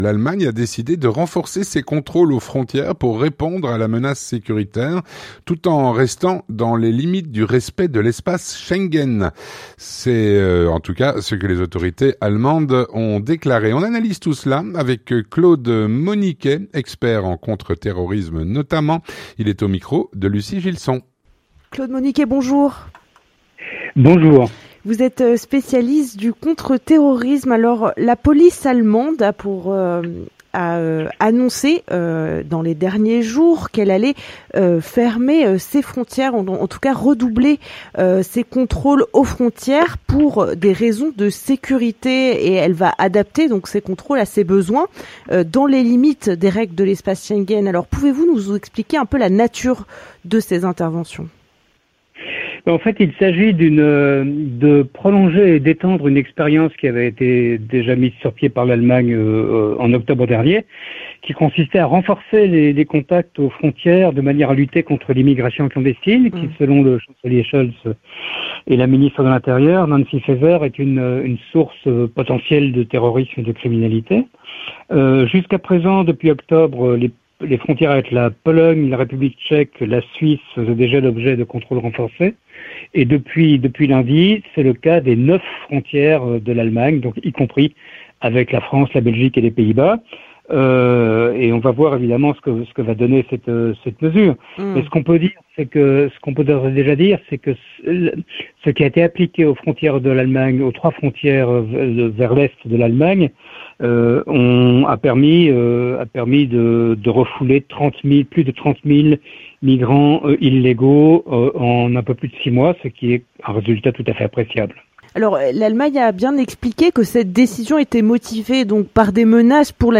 L'entretien du 18H - L’Allemagne a décidé de renforcer ses contrôles aux frontières pour répondre à la menace sécuritaire.